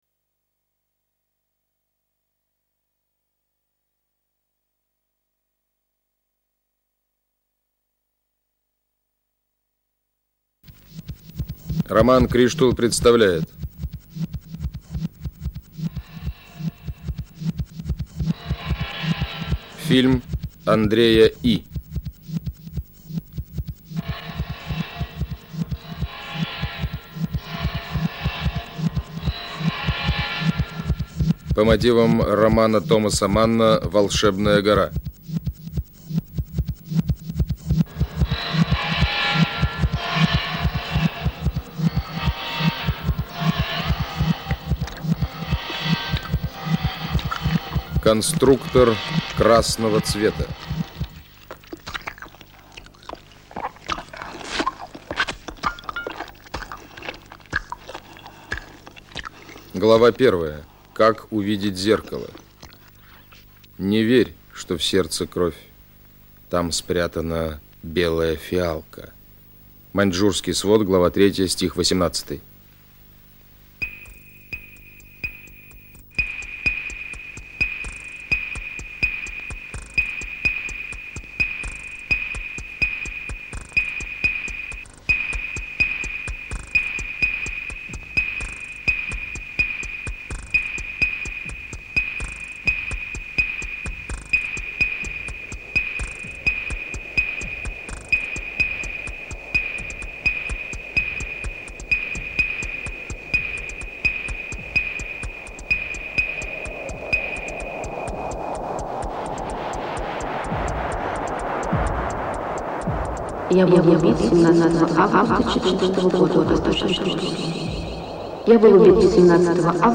soviet scientist trying to create a superhuman. strange old clips. pathology